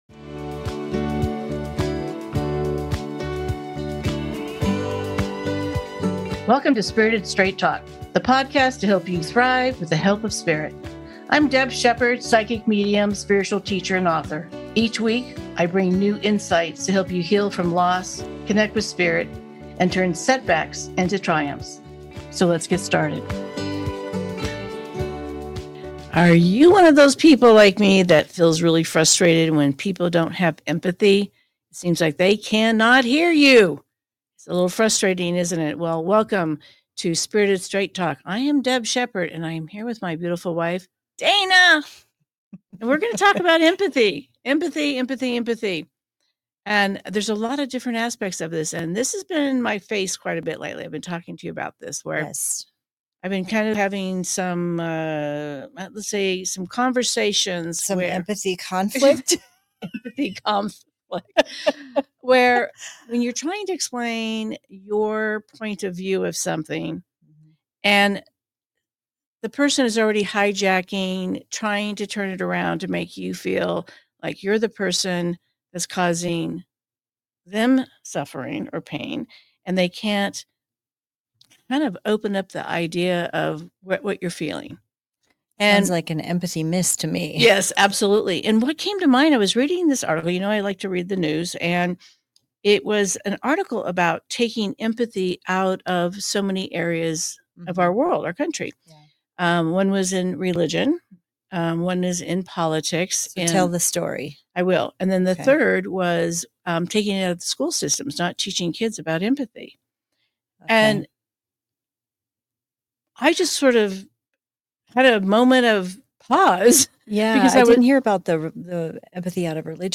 This is a heartfelt, honest conversation about empathy at every level—from our relationships and healing journeys to what it means for society as a whole.